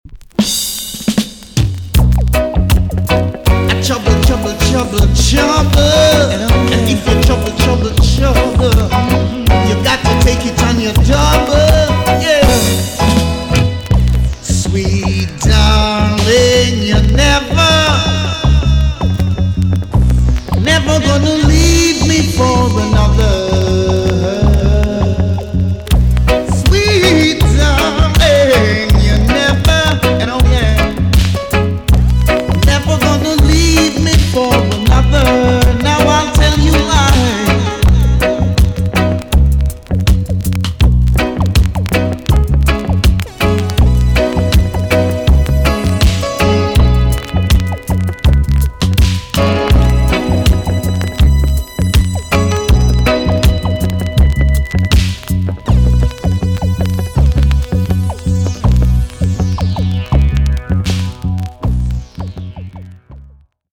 TOP >DISCO45 >80'S 90'S DANCEHALL
B.SIDE Version
EX- 音はキレイです。